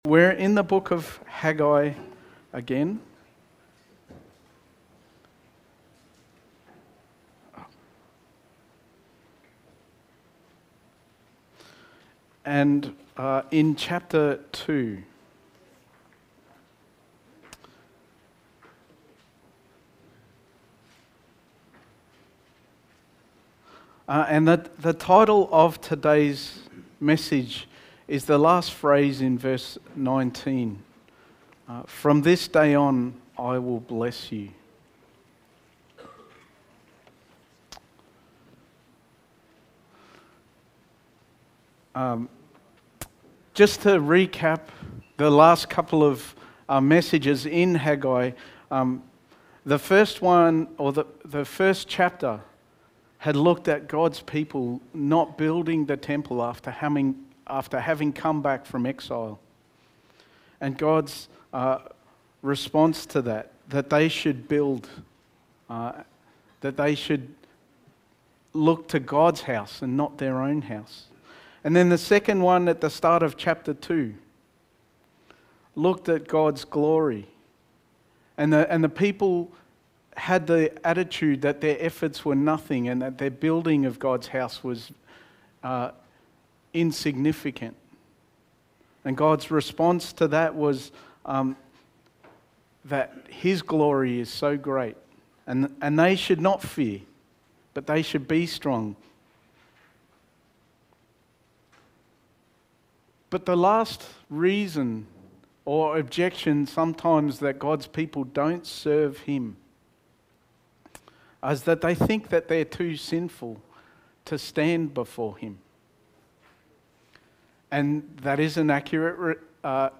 Haggai Series – Sermon 3: From This Day On I Will Bless You
Service Type: Sunday Morning